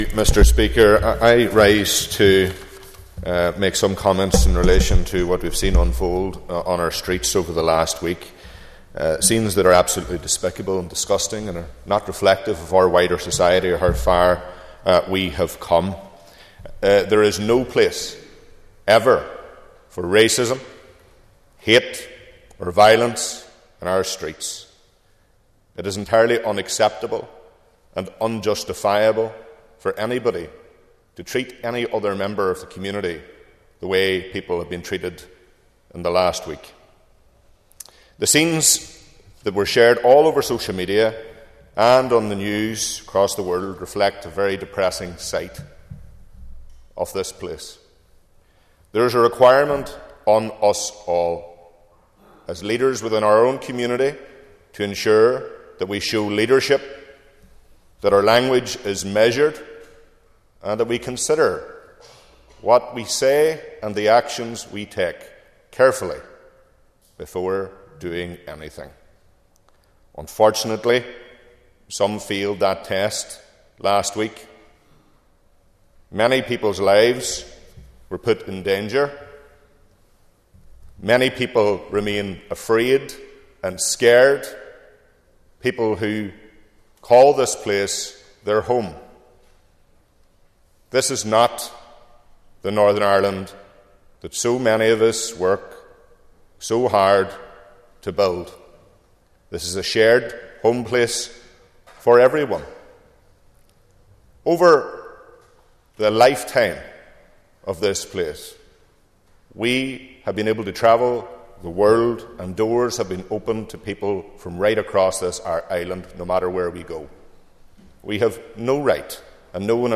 West Tyrone MLA Daniel McCrossan condemned the violence in the Northern Ireland Assembly: